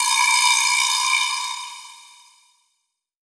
EVIBRASLAP.wav